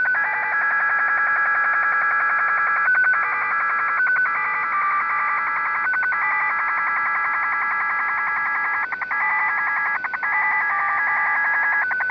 Japanese Navy 1500Bd QPSK
JAPANESE NAVY 1500 Bd QPSK SERIAL TONE WAVEFORM AUDIO SAMPLES Japanese Navy 1500 Bd serial tone waveform idling Japanese Navy 1500 Bd serial tone waveform idling, sending traffic and then back to idle state back to PSK-systems page
J-NY_1500Bd_QPSK-IDLE.WAV